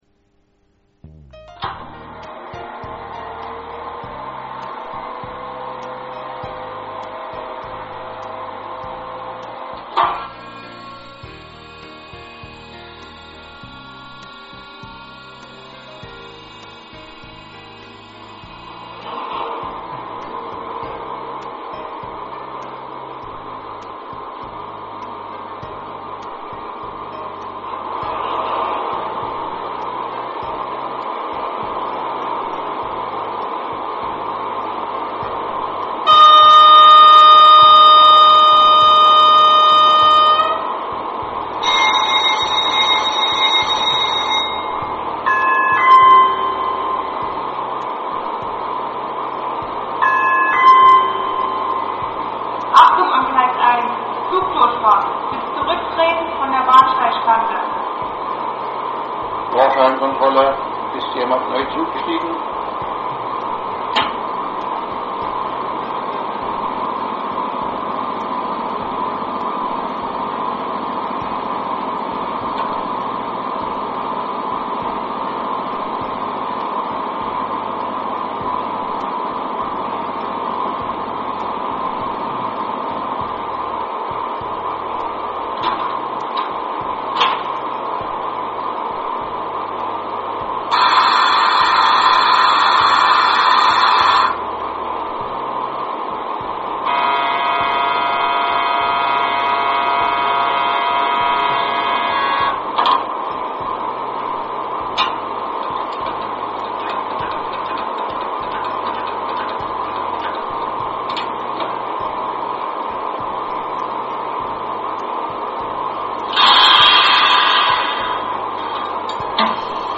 Der eMOTION XLS Sounddecoder gibt die authentischen Geräusche einer Lokomotive in hochwertiger digitaler Qualität wieder. Hierzu werden Soundaufnahmen direkt am Vorbild vorgenommen und dann im Soundlabor für die Elektronik abgeglichen.
• Standgeräusch
• Fahrgeräusch
Die Hintergrundmusik in den MP3-Demo Dateien ist nicht im XLS-Modul vorhanden!
Soundgeräusch